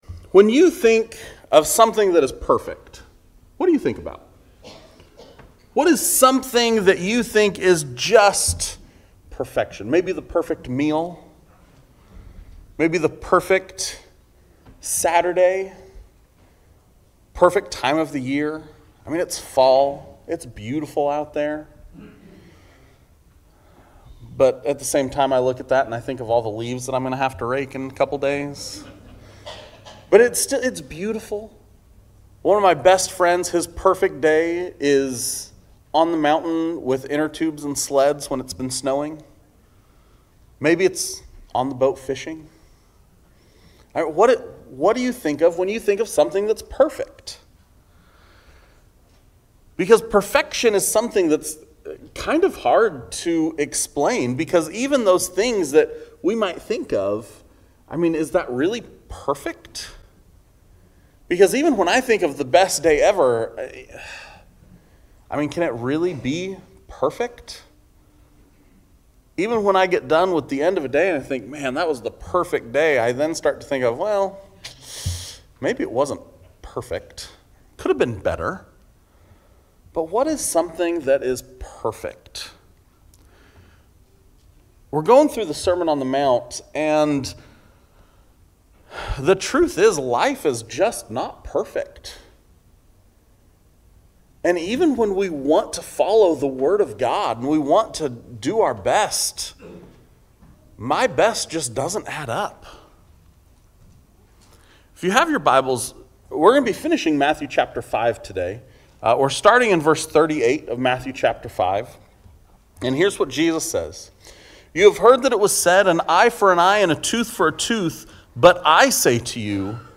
Sermons | Oregon City Church of Christ